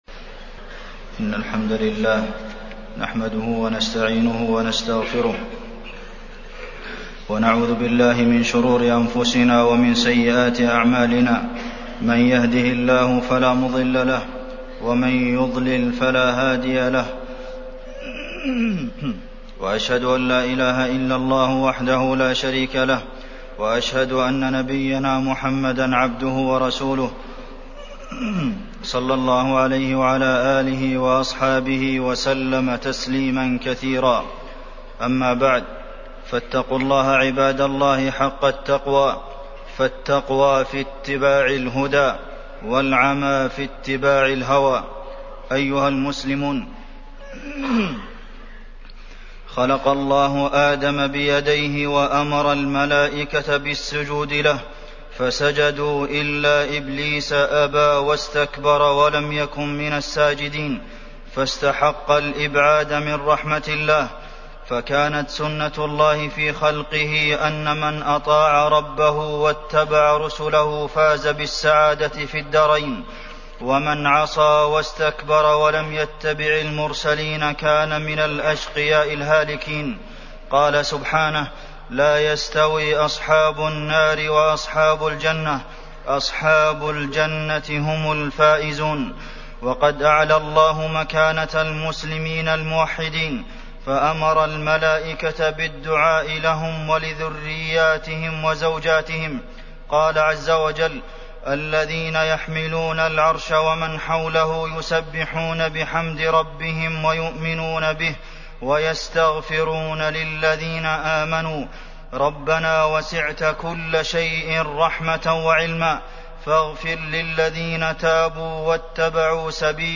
تاريخ النشر ٢٩ ذو الحجة ١٤٢٧ هـ المكان: المسجد النبوي الشيخ: فضيلة الشيخ د. عبدالمحسن بن محمد القاسم فضيلة الشيخ د. عبدالمحسن بن محمد القاسم مكانة الفرد المسلم في الإسلام